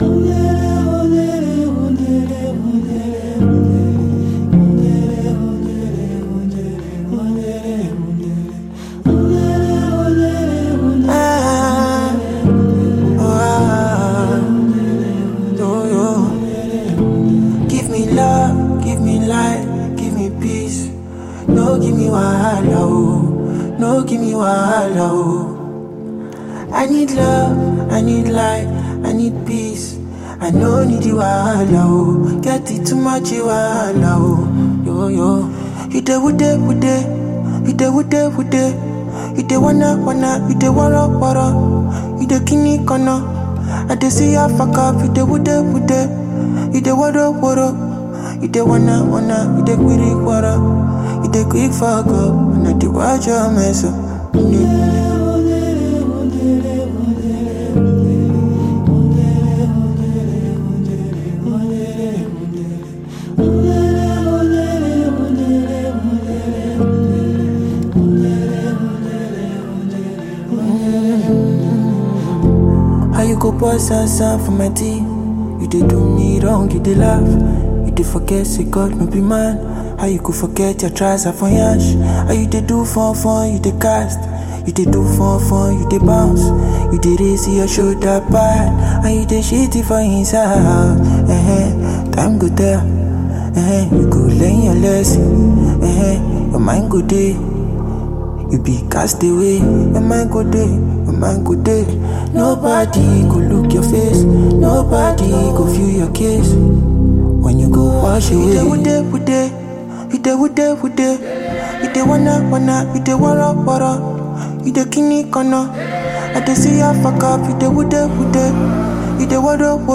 African Music Genre: Afrobeats Released